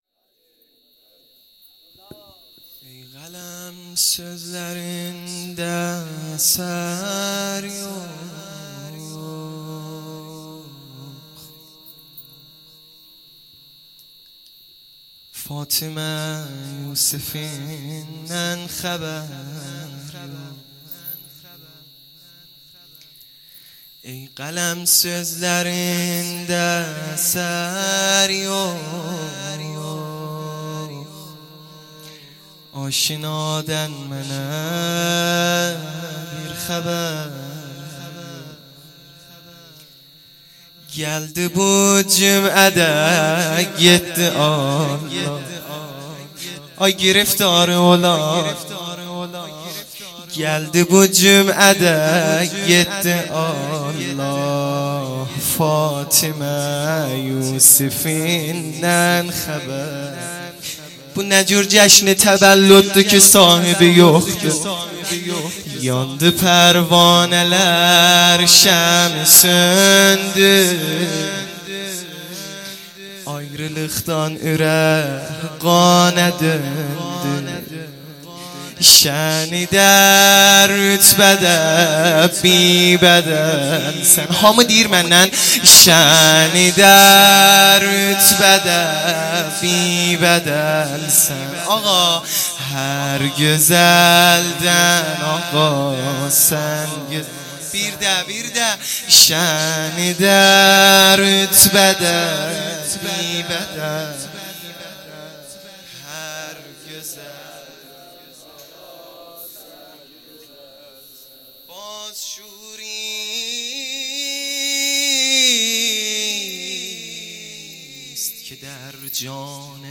مدح
جشن نیمه شعبان